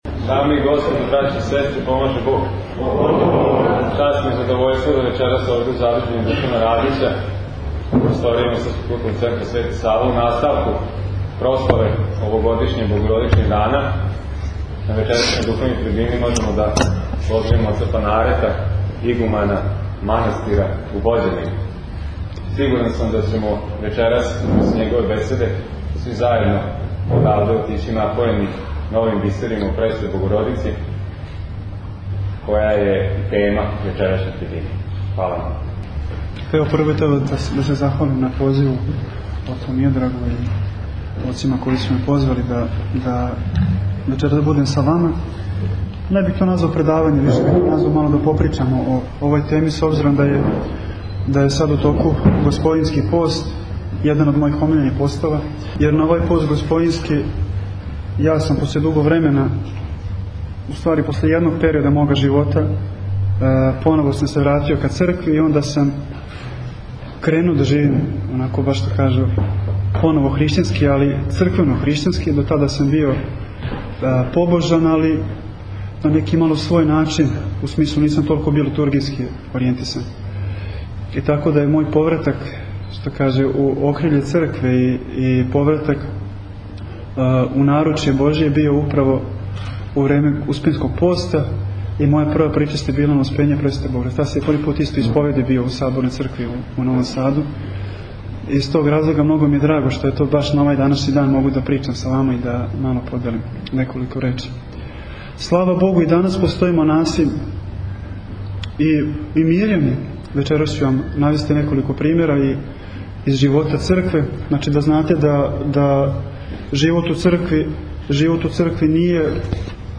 Духовна трибина у Суботици